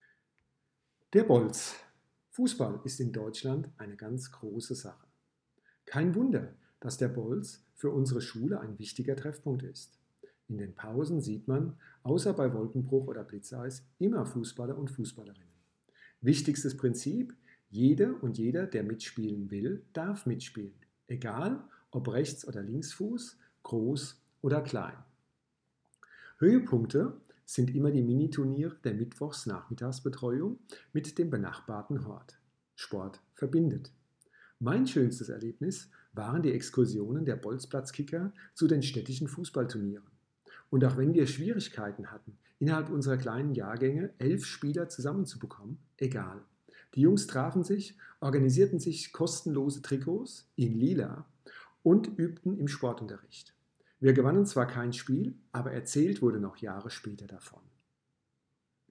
Lehrer an der FCS über den Bolzplatz